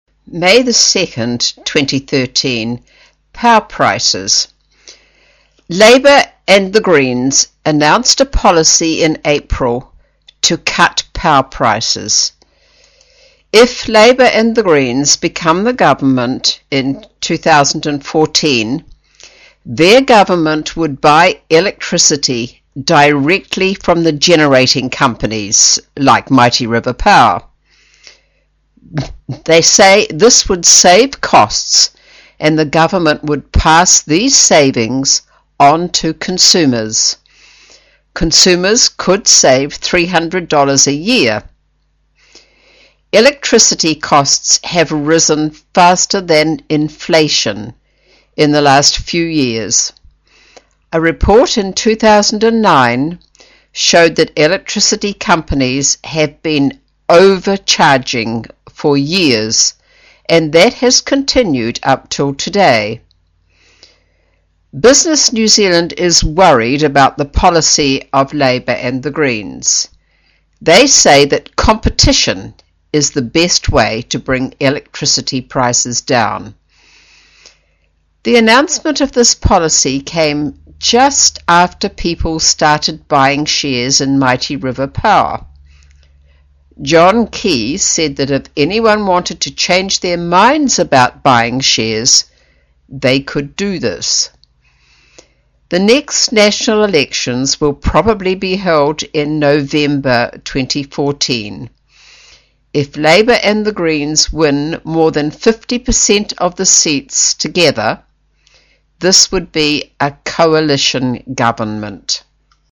新西兰英语 777 Power prices 听力文件下载—在线英语听力室